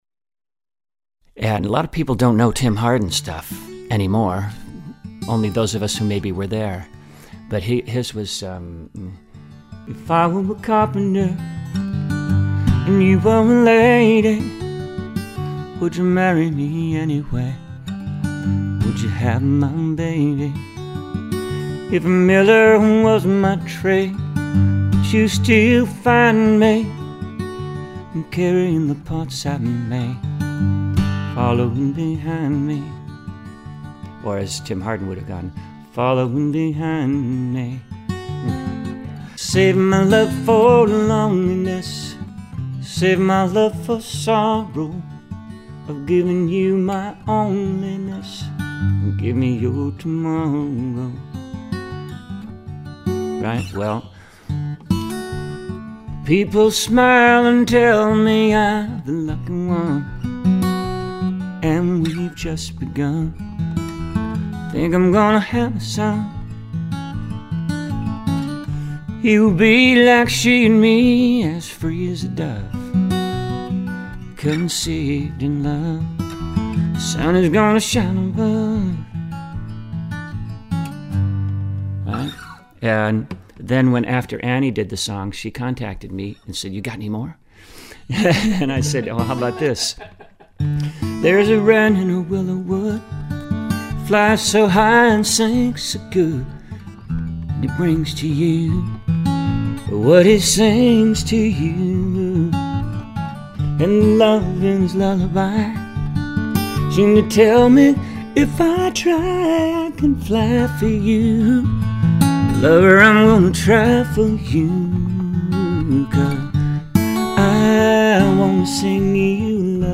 spontaneous medley
was performed  live during an interview